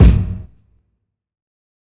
000_Lo-Fi Classic Kick.wav